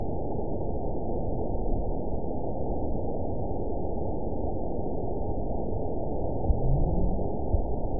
event 920382 date 03/20/24 time 23:19:56 GMT (1 year, 1 month ago) score 8.49 location TSS-AB04 detected by nrw target species NRW annotations +NRW Spectrogram: Frequency (kHz) vs. Time (s) audio not available .wav